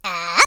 share/hedgewars/Data/Sounds/voices/HillBilly/Jump1.ogg
07d83af9d2a8 Add hillbilly voice